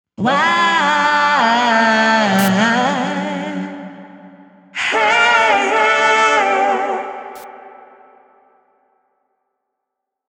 素材は 「DecaBuddy」 の時のコーラスを使いました。
（Amplitube はデモ版のプロテクトで、“ザッ” というノイズが入ります。）
Amplitube FXReverb
減衰音を聞きやすいように、かなり強めのリバーブをかけてみました。
Amplitube の場合 “リード楽器を際立たせる” という意図がはっきり出ています。
また、減衰音も “空間” を感じさせてくれて、見事だと思います。